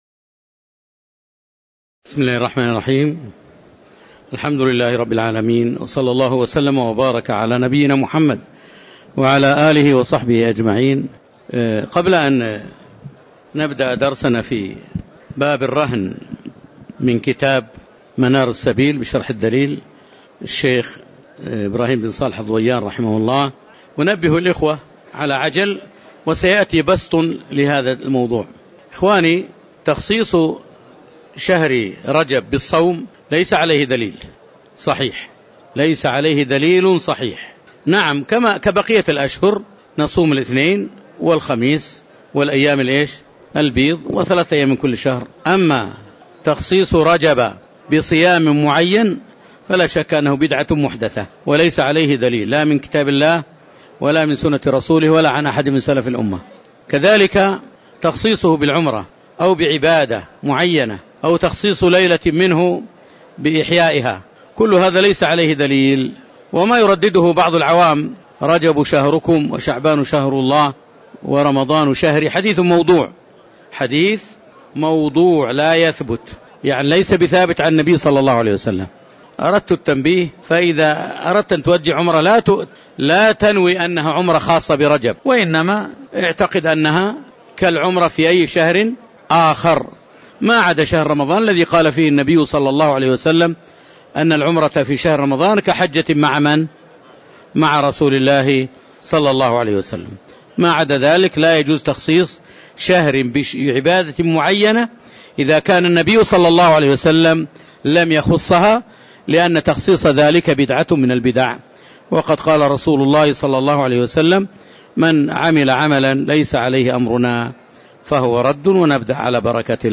تاريخ النشر ٧ رجب ١٤٤٠ هـ المكان: المسجد النبوي الشيخ